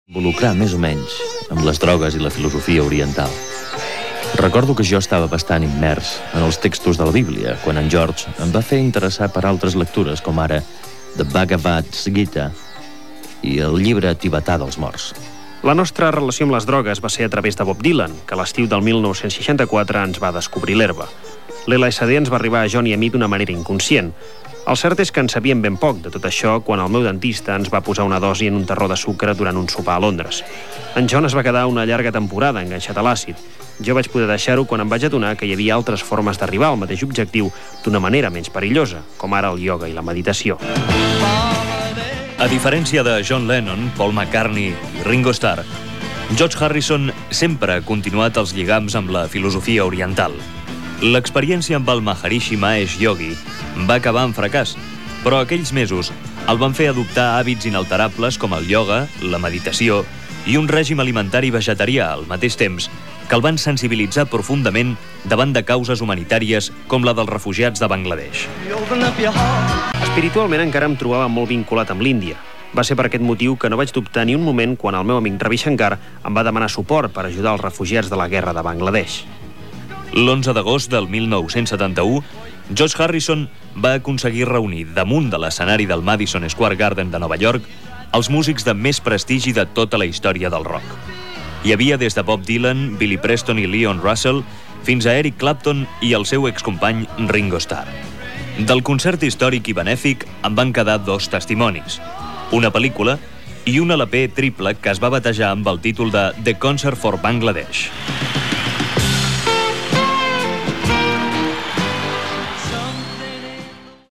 Reportatge sobre el músic George Harrison.
Musical